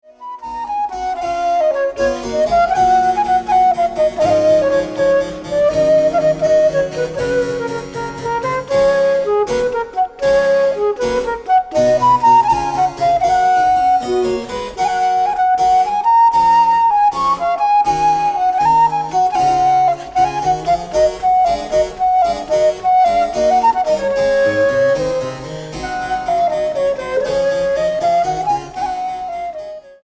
Música barroca portuguesa y su influencia italo-española
Tepee Studio-Brussels